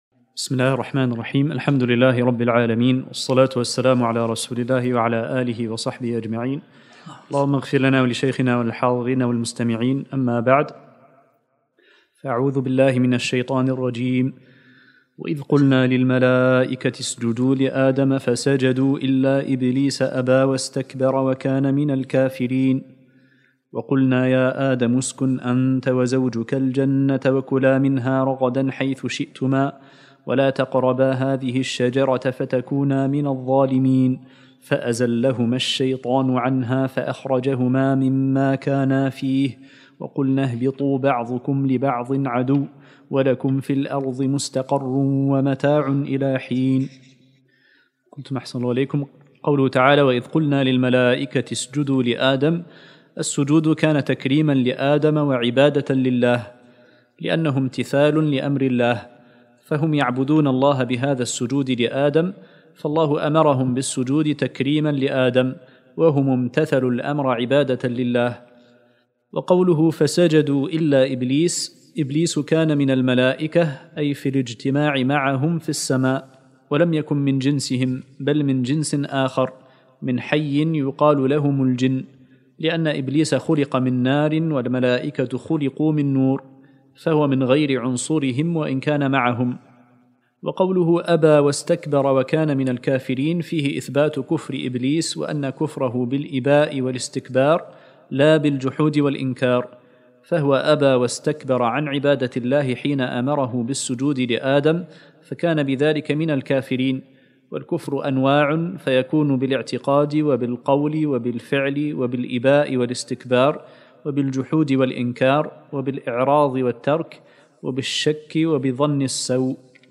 الدرس الثالث من سورة البقرة